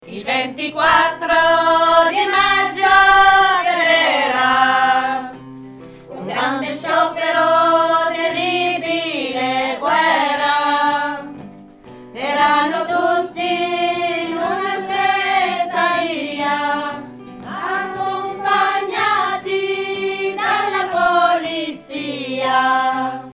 Mondine_contro_soprani.mp3